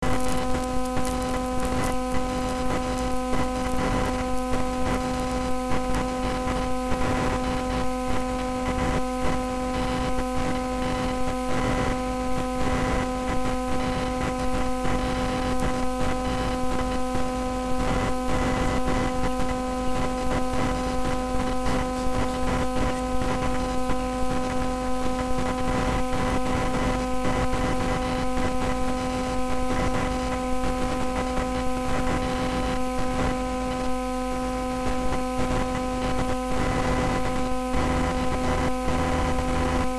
Â Set up induction loop close to extension socket with microphone. Â Talk into the microphone to see if the ground of power supply will transmit your words into the recording computer through electromagnetic induction.
26 aug computer to ground power supply record via em induction 1 to 10
26-aug-computer-to-ground-power-supply-record-via-em-induction-1-to-10.mp3